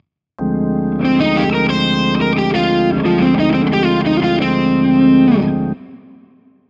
JASON BECKER ARPEGGI IN TAPPING